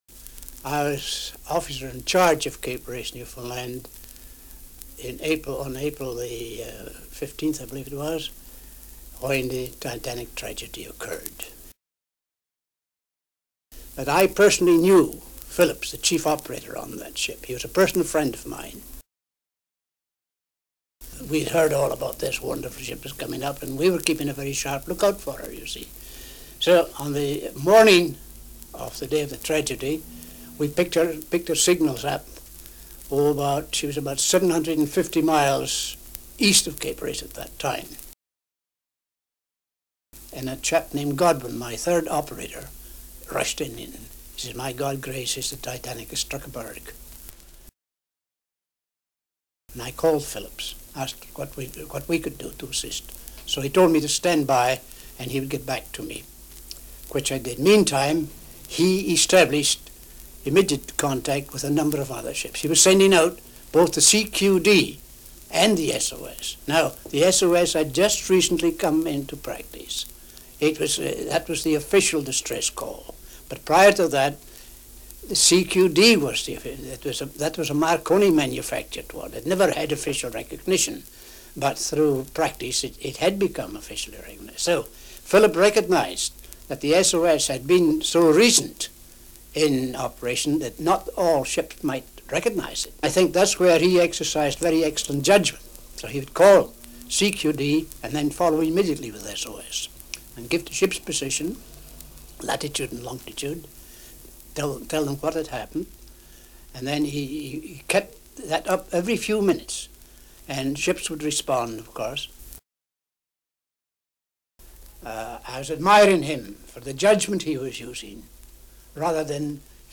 Qui potete ascoltare le voci di chi visse, in prima persona, il disastro del TITANIC nel 1912. Queste registrazioni originali sono state gentilmente concesse dall'Archivio della BBC.